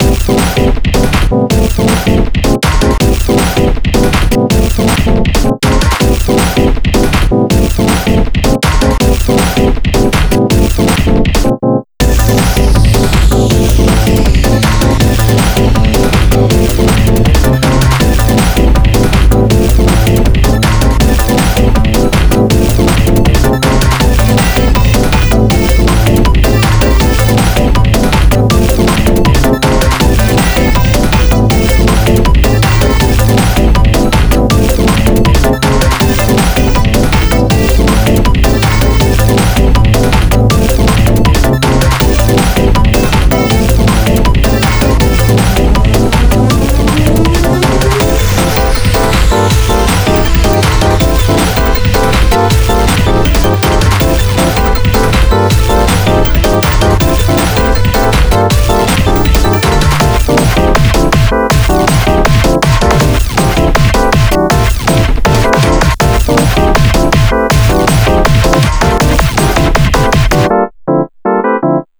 Soundtrack　 BPM:160　 Drum'n'Bass
ループ かっこいい 疾走感